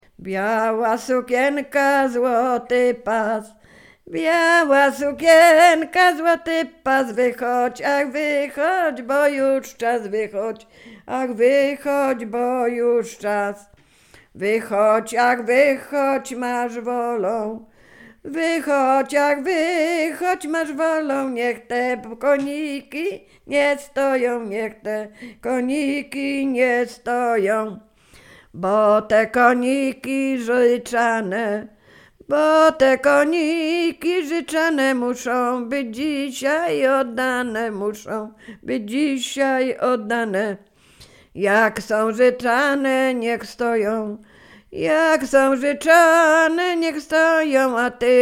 Sieradzkie
Weselna